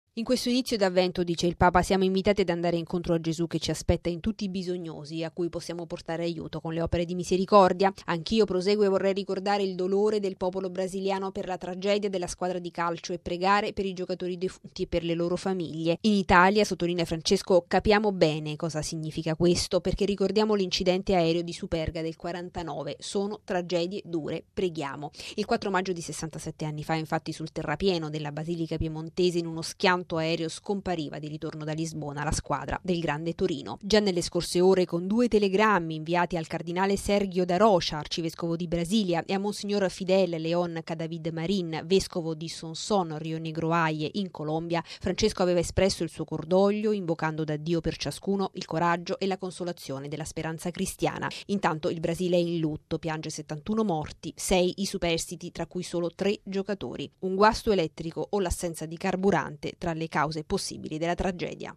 Al termine dell’udienza generale, salutando i pellegrini di lingua portoghese, il Papa è tornato col pensiero al dolore del popolo brasiliano per la tragedia che, tra lunedì e martedì, ha coinvolto un volo di linea diretto in Colombia. 71 i morti, inclusa la squadra di serie A della Chapecoense.